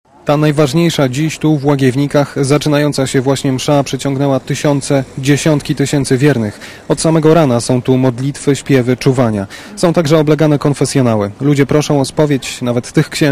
W Łagiewnikach, miejscu tak bardzo związanym z papieżem zaczyna się najważniejsza msza w Święto Miłosierdzia Bożego. Na msze przyszły tłumy wiernych.
Relacja